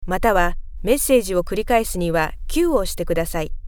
Professionelle japanische Sprecherin für TV / Rundfunk / Industrie.
Kein Dialekt
Sprechprobe: Werbung (Muttersprache):